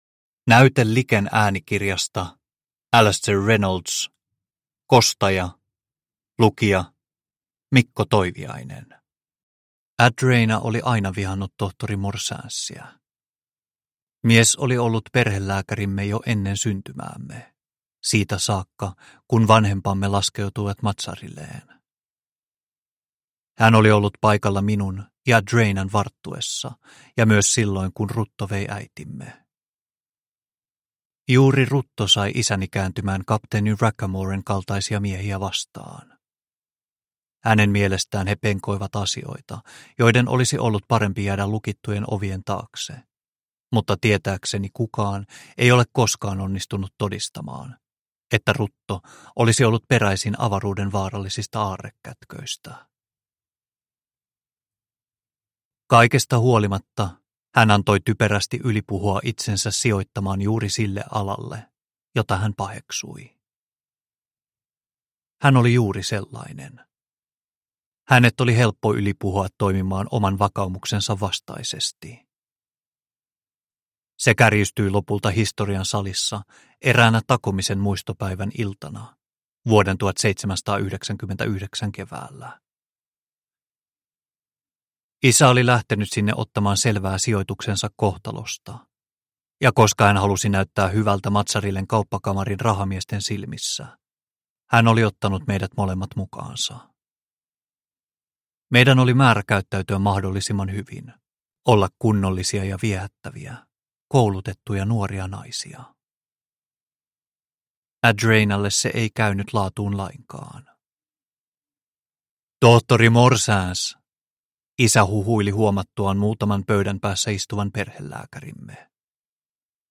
Kostaja – Ljudbok – Laddas ner